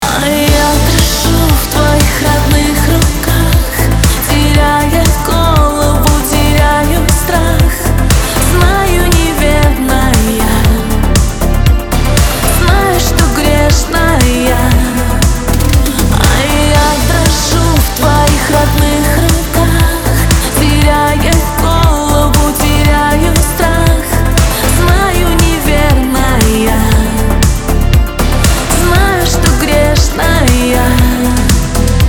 Поп женский голос